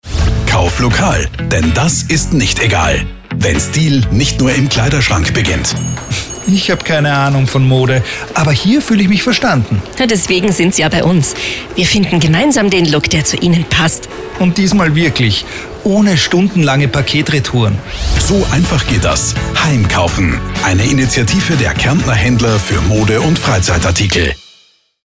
Radiospot Mode
radiospot-mode-2025.mp3